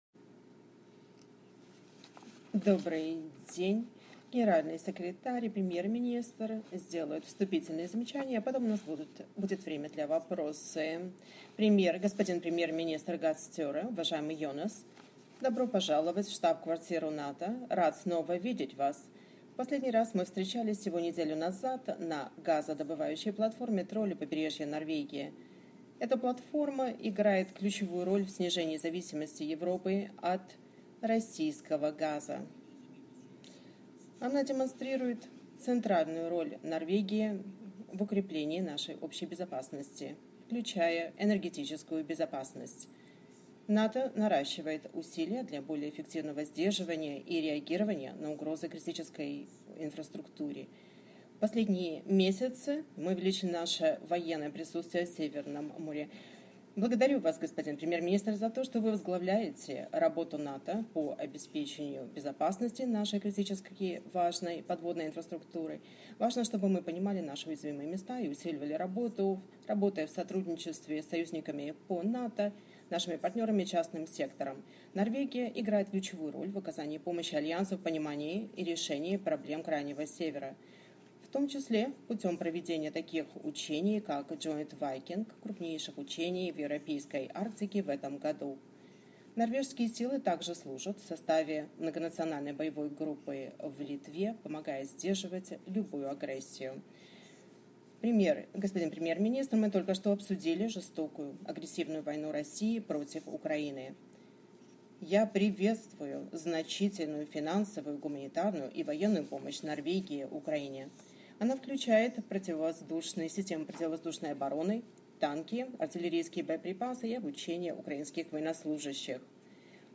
Joint press point with NATO Secretary General Jens Stoltenberg and the Prime Minister of Norway, Jonas Gahr Støre